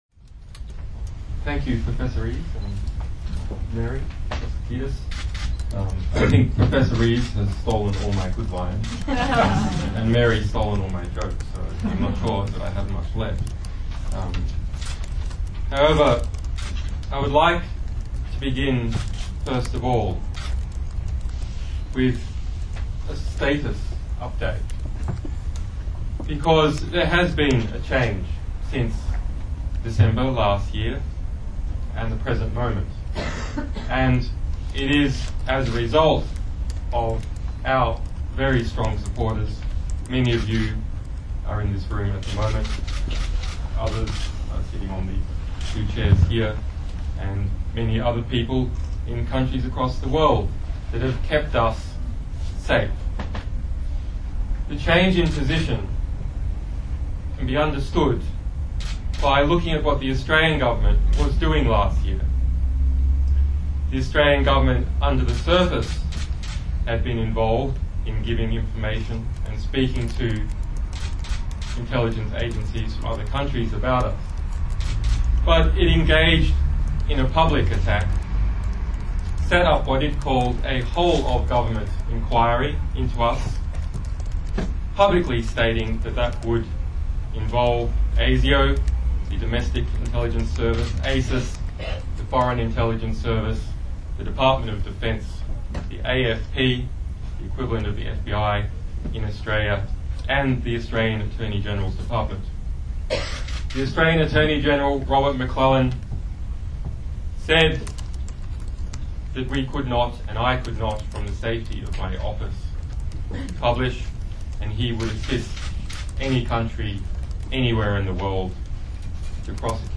Julian Assange Sydney Peace Prize acceptance speech